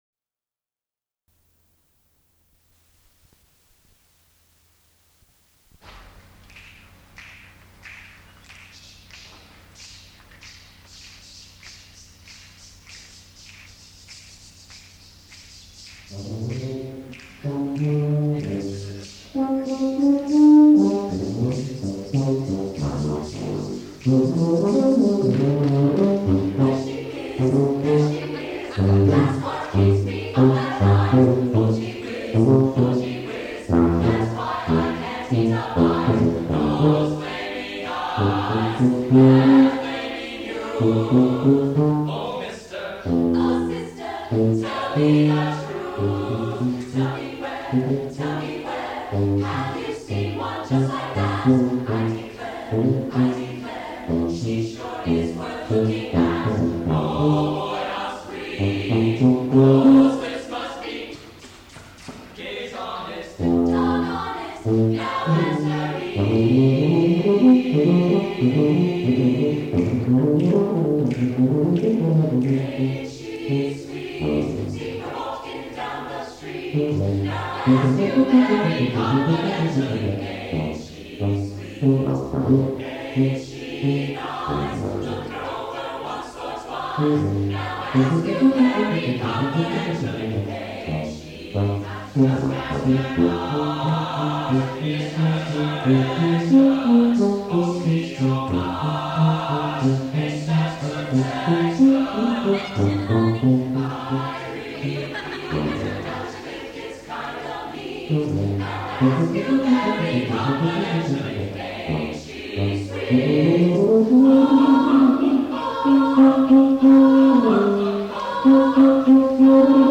for SATB Chorus and Tuba (1998)
tuba